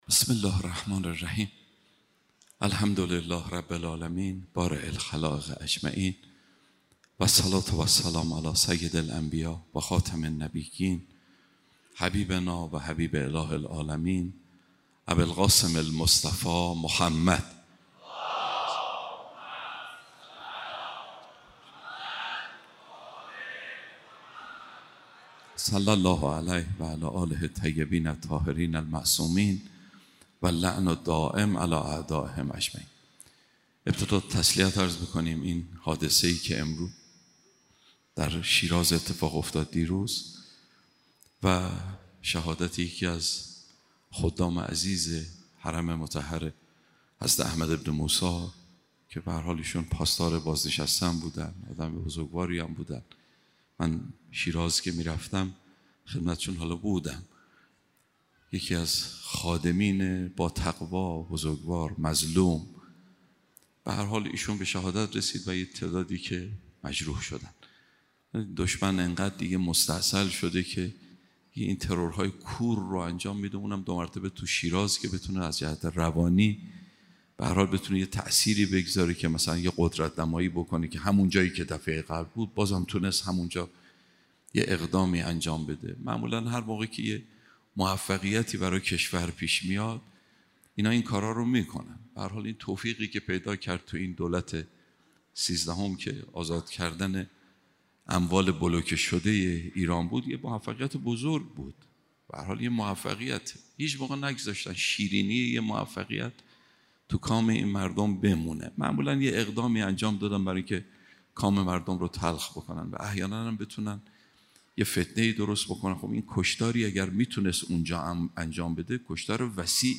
سخنرانی دهه اول محرم 1402